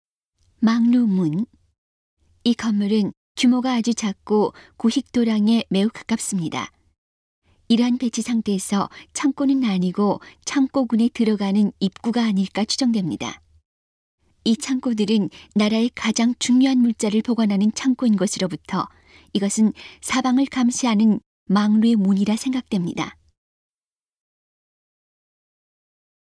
음성 가이드 이전 페이지 다음 페이지 휴대전화 가이드 처음으로 (C)YOSHINOGARI HISTORICAL PARK